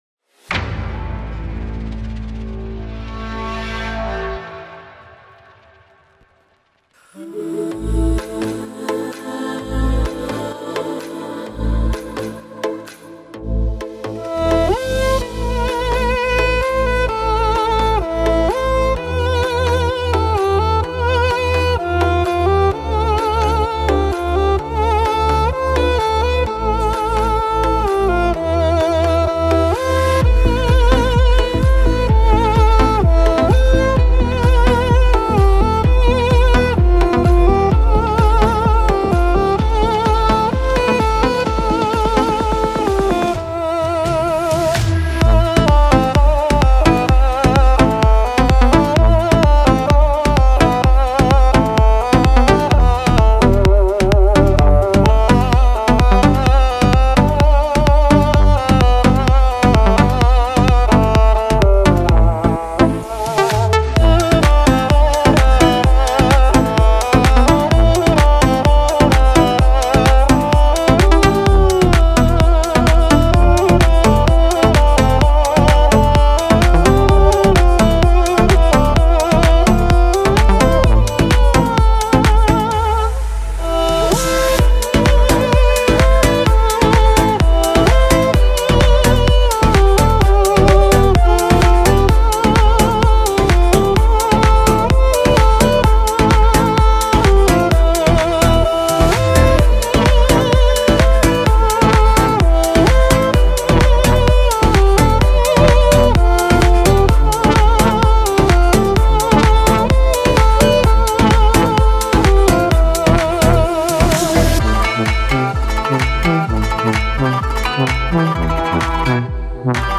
это трогательная песня в жанре поп
нежный вокал и выразительные мелодии